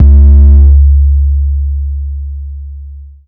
Waka KICK Edited (36).wav